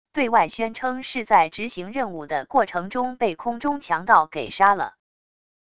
对外宣称是在执行任务的过程中被空中强盗给杀了wav音频生成系统WAV Audio Player